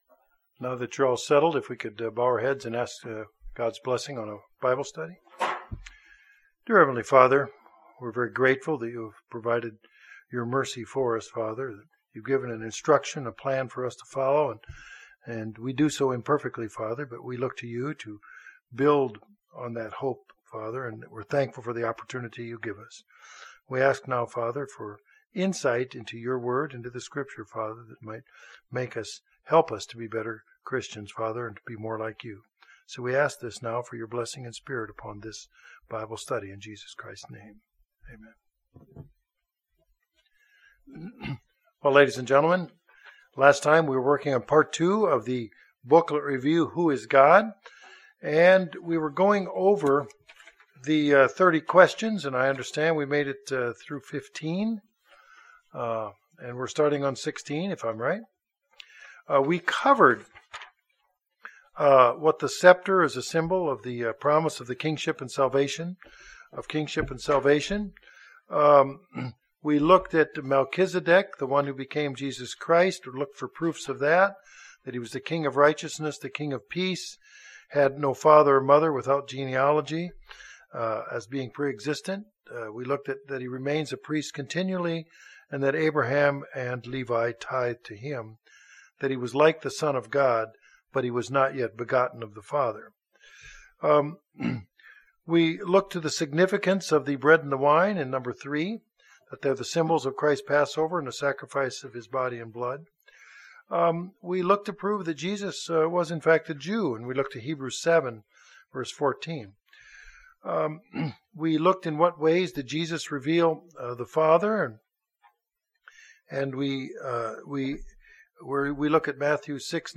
This is the final installment of a three part Bible Study series using the study aid, "Who Is God?," to discover how the Bible identifies God and how God relates to humanity.
Given in Northwest Arkansas